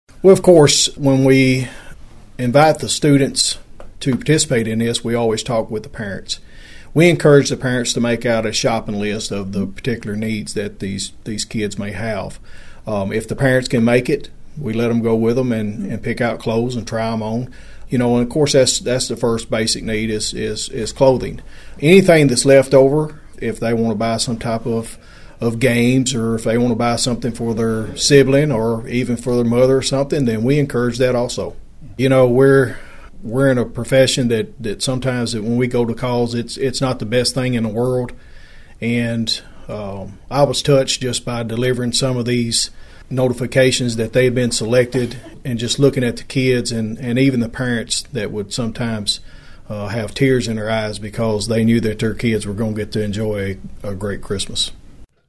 Sheriff Parker explained Saturday’s event with the local officers and children.(AUDIO)